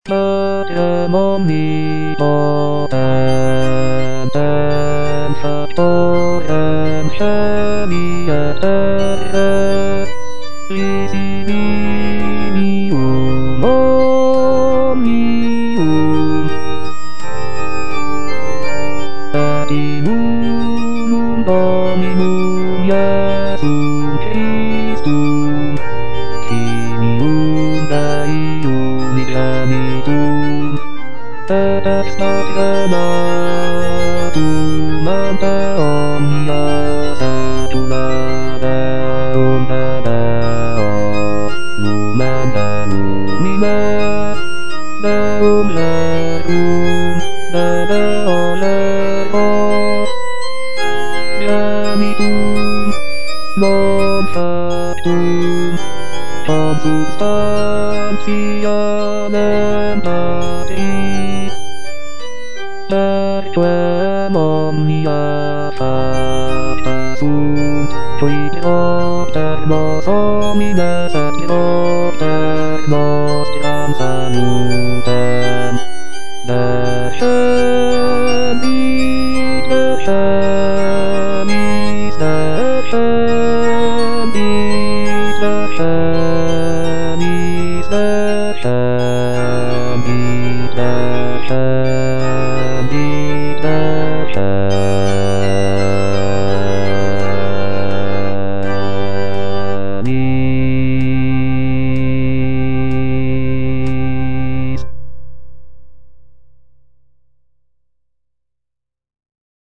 J.G. RHEINBERGER - MASS IN C OP. 169 Credo - Patrem omnipotentem - Bass (Voice with metronome) Ads stop: auto-stop